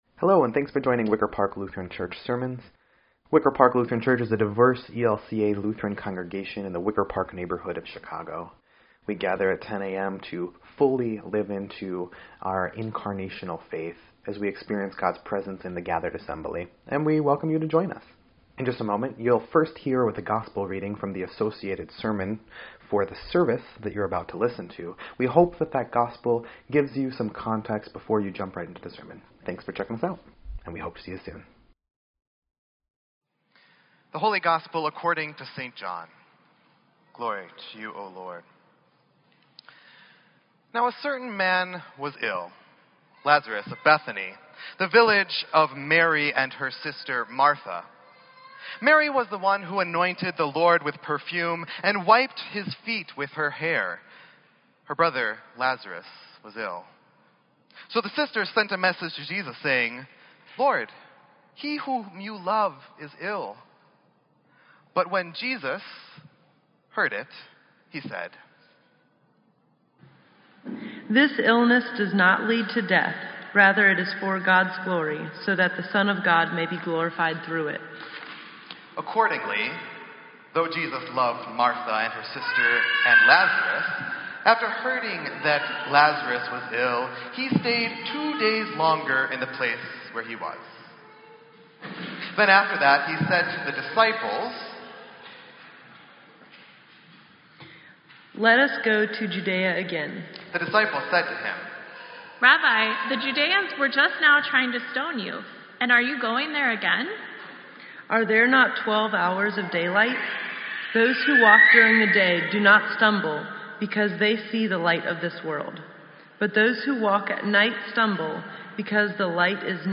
Sermon_4_2_17_EDIT.mp3